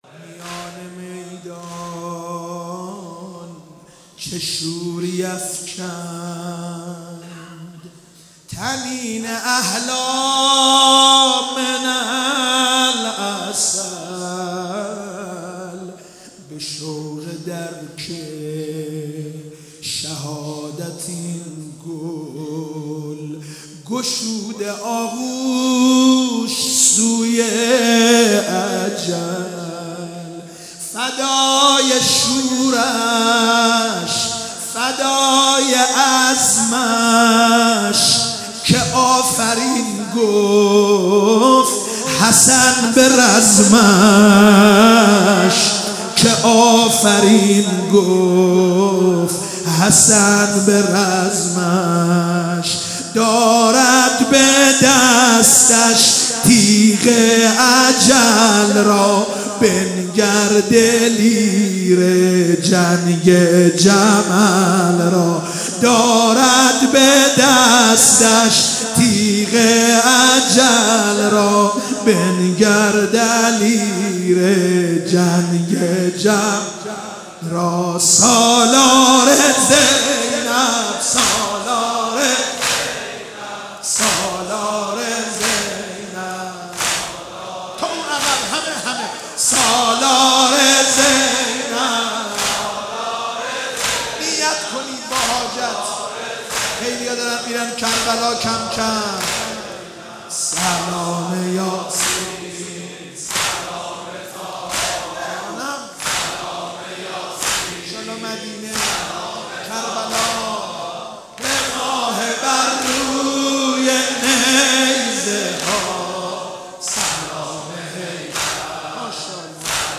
نوحه جدید